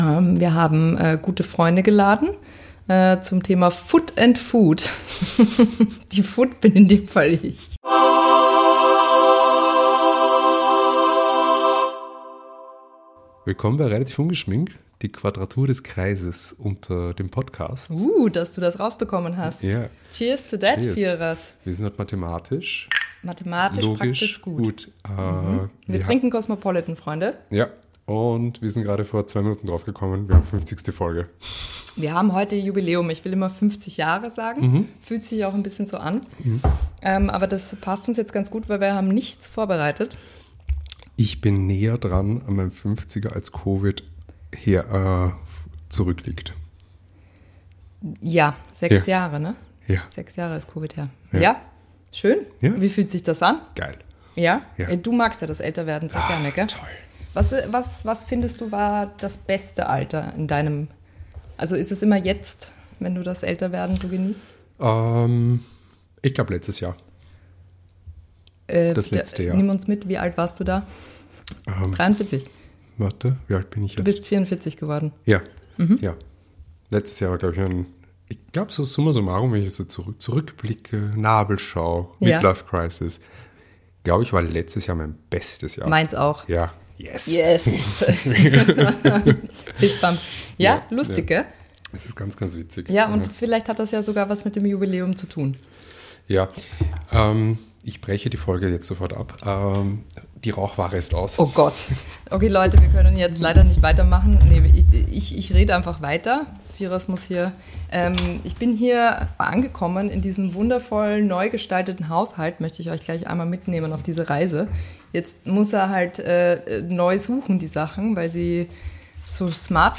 Intro: Sound Effect